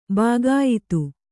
♪ bāgāyitu